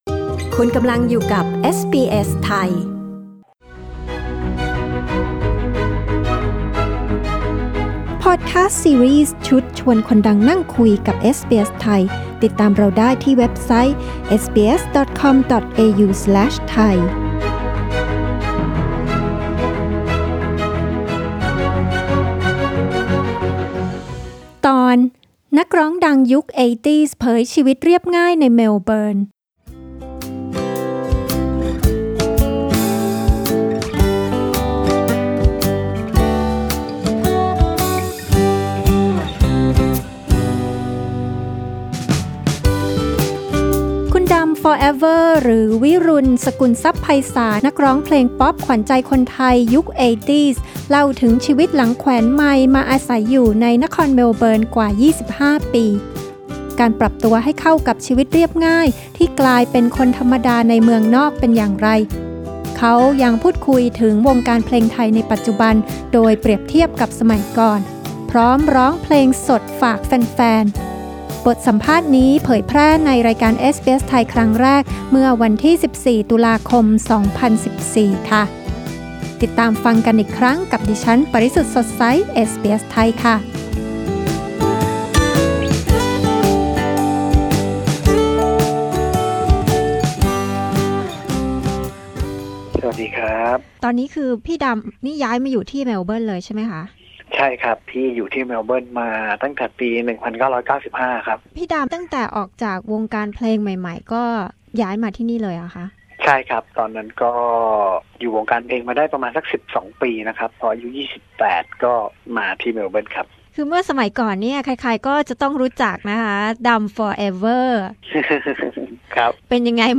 ดำ ฟอร์เอเวอร์ หรือ วิรุฬ สกุลทรัพย์ไพศาล นักร้องเพลงป๊อบขวัญใจคนไทยยุค 80 เล่าถึงชีวิตหลังแขวนไมค์มาอาศัยอยู่ในเมลเบิร์นมากว่า 25 ปี การปรับตัวให้เข้ากับชีวิตเรียบง่าย ที่กลายเป็นคนธรรมดาในเมืองนอกเป็นอย่างไร เขาพูดคุยถึงวงการเพลงไทยในปัจจุบันโดยเปรียบเทียบกับสมัยก่อน พร้อมร้องเพลงสดฝากแฟนๆ…